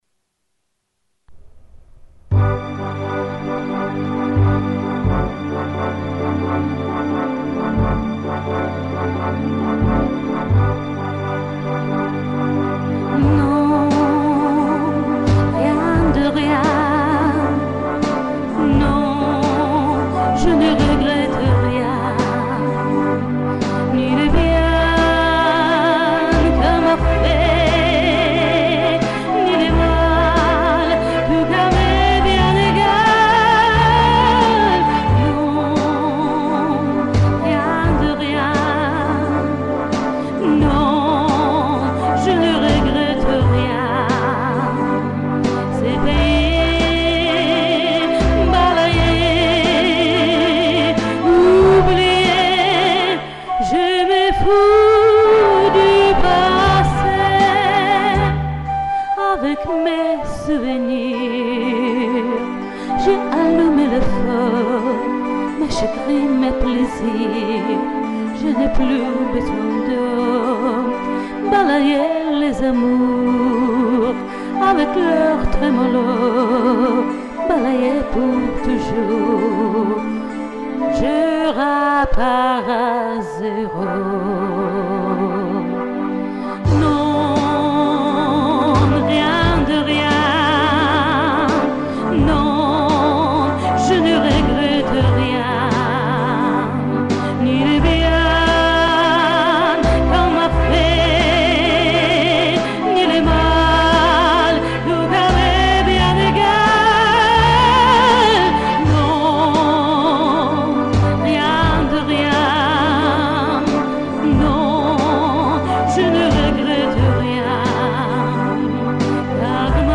во-вторых, подача... ух... как сильно и мощно!!!))))